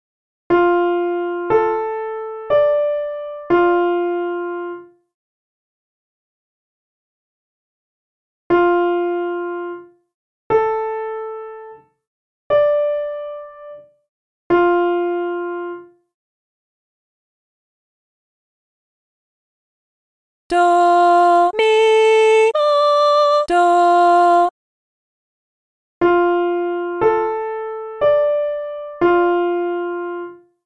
If you need a hint, listen to the hint clip which will play the melody more slowly and then reveal the solfa syllables.
(key: F Major)